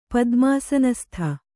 ♪ padmāsanastha